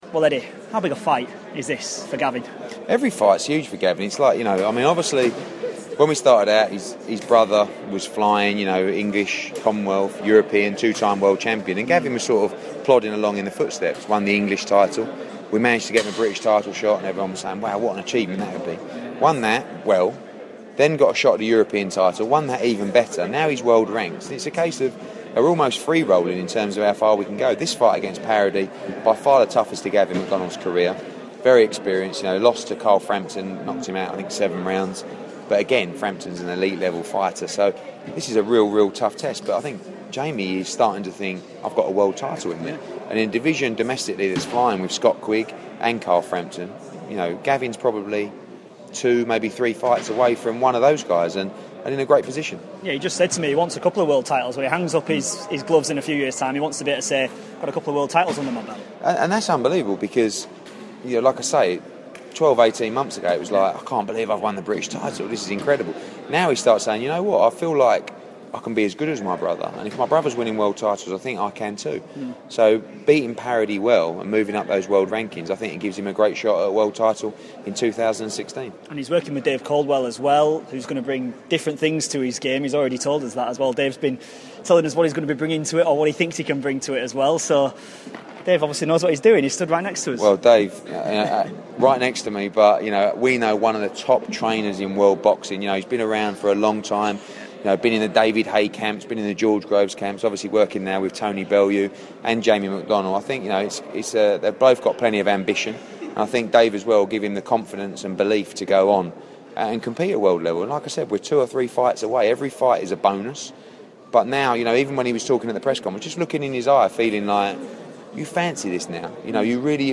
INTERVIEW: Promoter Eddie Hearn talks about Gavin McDonnell's next bout and the latest on Kell Brook